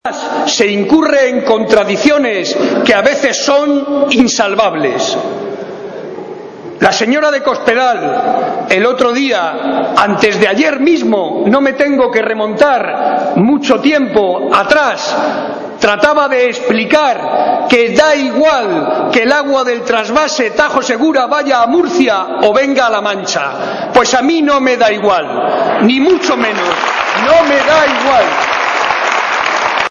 un acto en el que participaron cerca de 800 personas